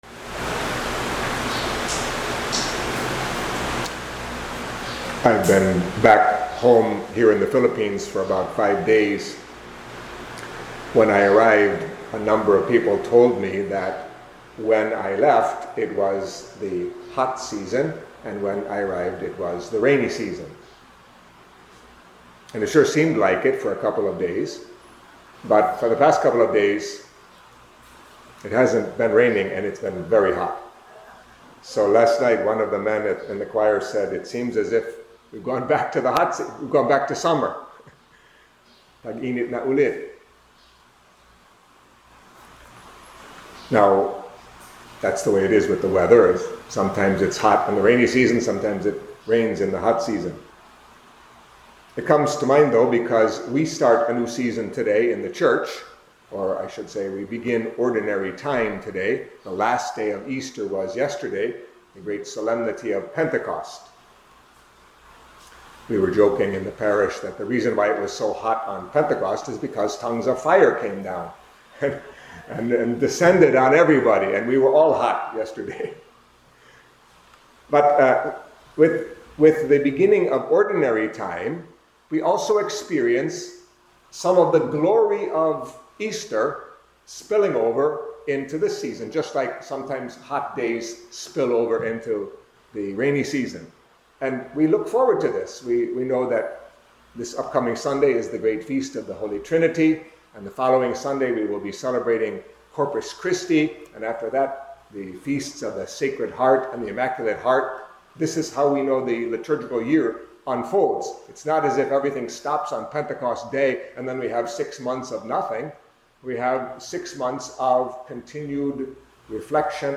Catholic Mass homily for the feast of Mary, Mother of the Church